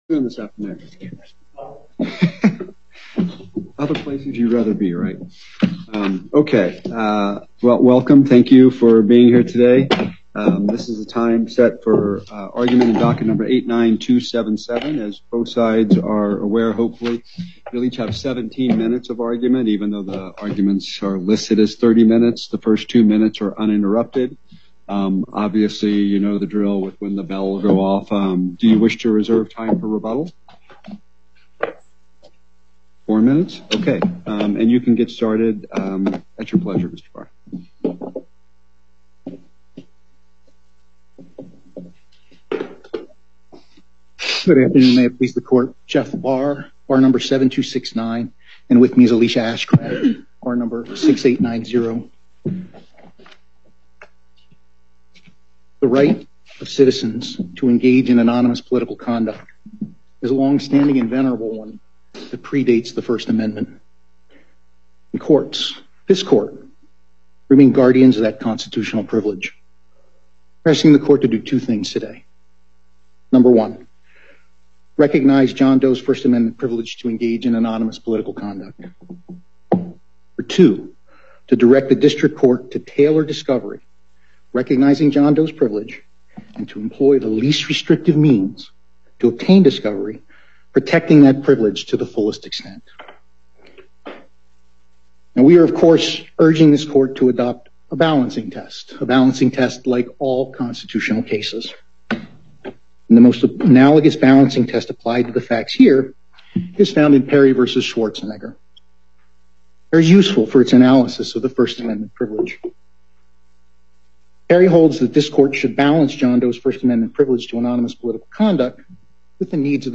Before the En Banc Court, Chief Justice Herndon presiding